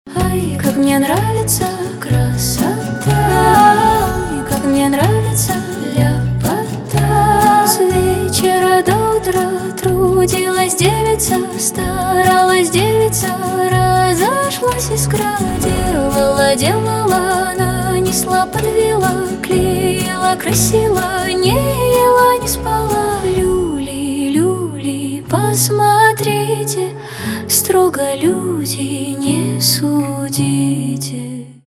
поп , нейросеть , женские